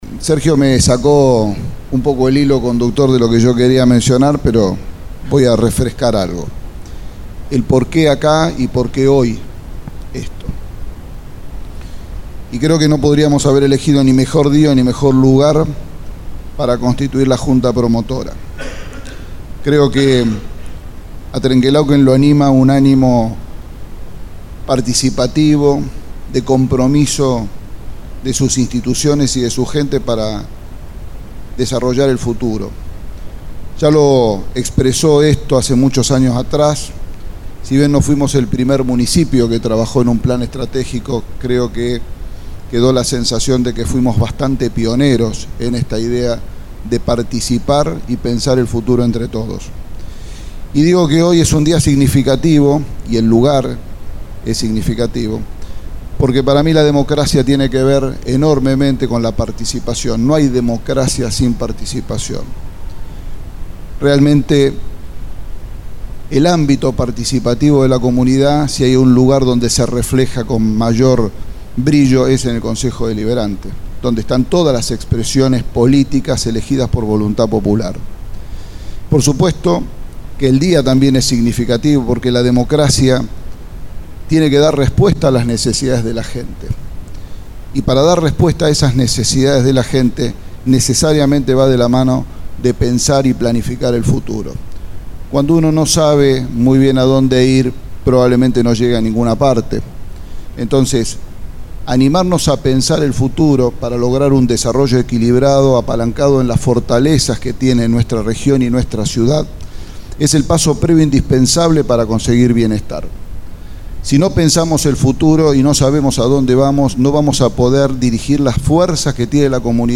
Miguel Fernández Intendente Municipal, estuvo en firma del Acta Constitutiva de la Junta Promotora del Plan Estratégico de Trenque Lauquen – Agenda Estratégica Trenque Lauquen 2030 y así se dirigió a los presentes.